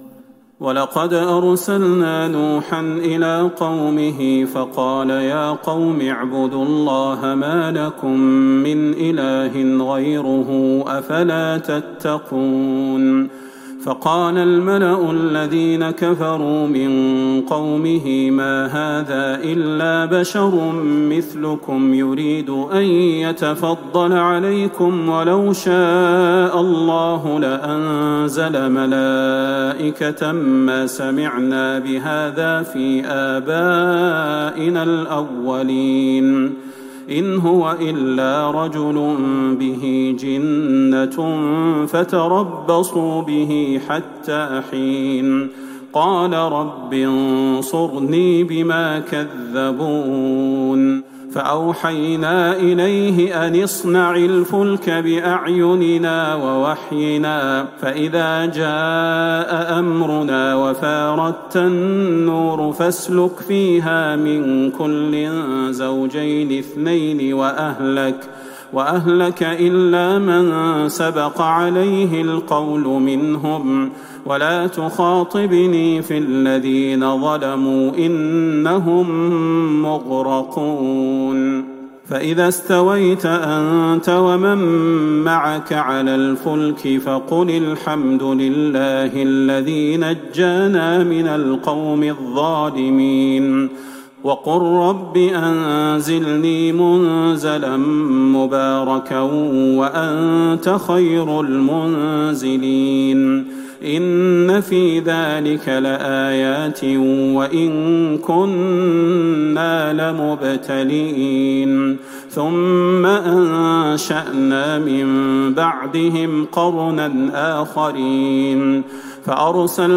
تراويح ٢٢ رمضان ١٤٤١هـ من سورة المؤمنون { ٢٣-١١٨ } > تراويح الحرم النبوي عام 1441 🕌 > التراويح - تلاوات الحرمين